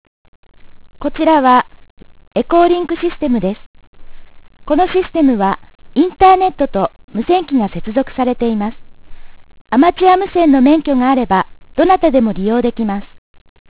EchoLink Announce File 「こちらはエコ-リンクシステムです・・・・・インターネットと繋がっています」の音声（女性）ファイルです。
echolinksystem.wav